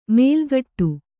♪ mēlgaṭṭu